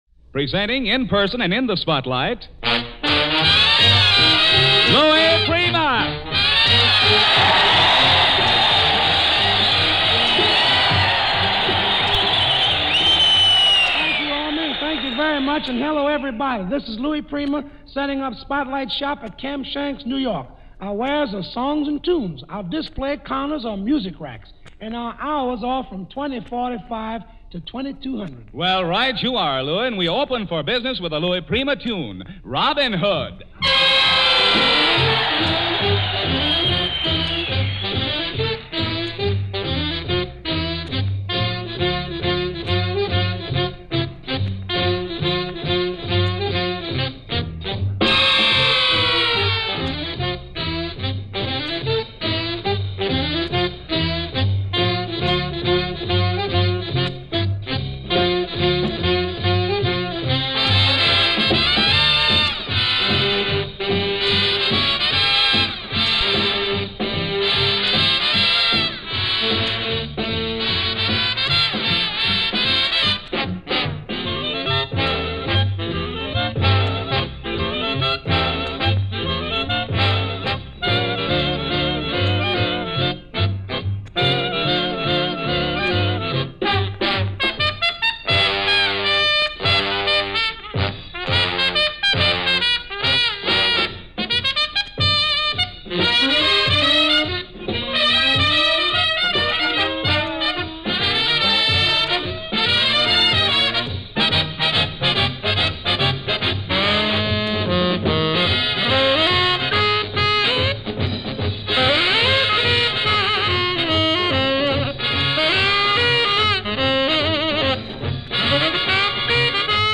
live from Camp Shanks, New York 1946
Big Band Swing edition
vocals